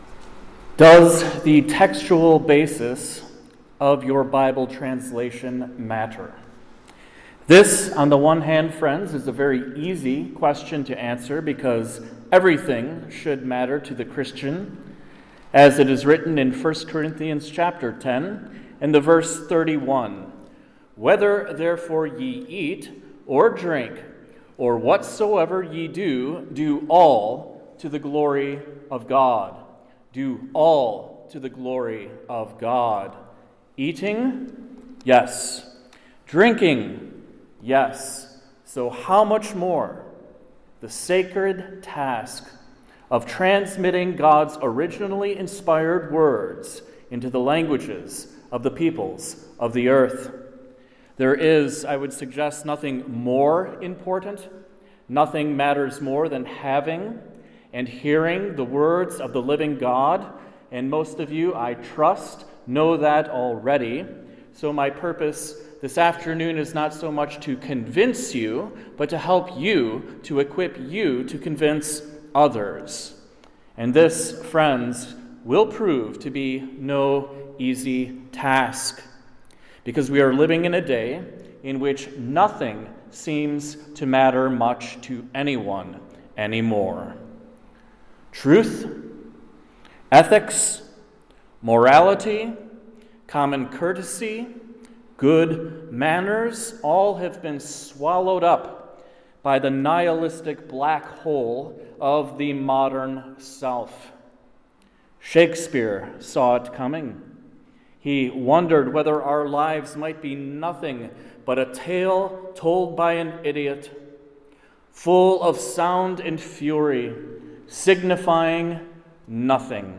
Textual Basis of Your Bible | SermonAudio Broadcaster is Live View the Live Stream Share this sermon Disabled by adblocker Copy URL Copied!